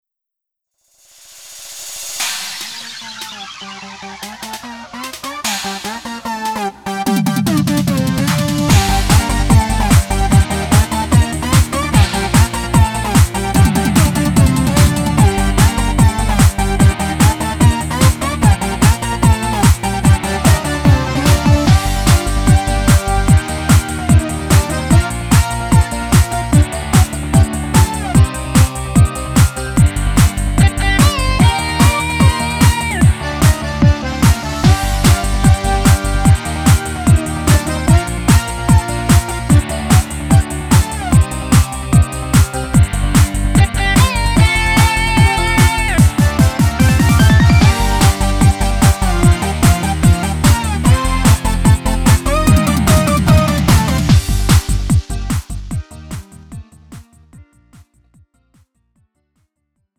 음정 -1키 3:33
장르 가요 구분 Lite MR